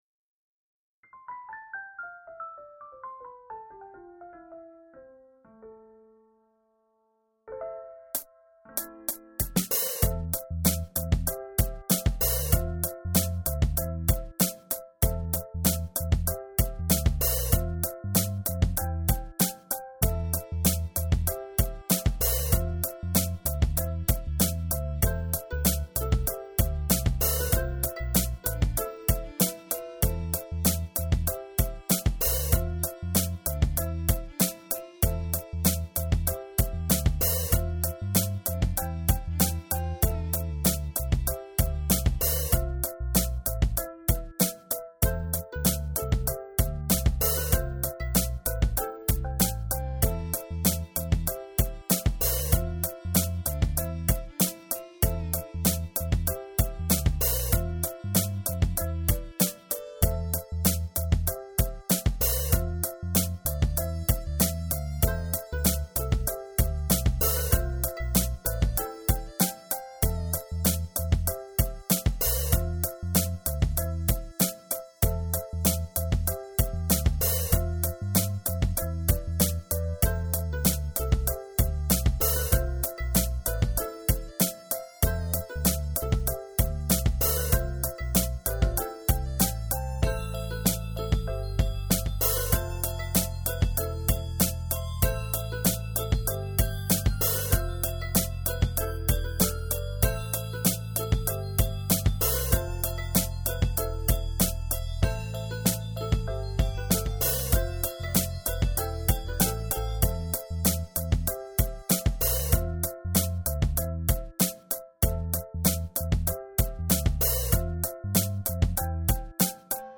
A smooth beat; for the poet at heart...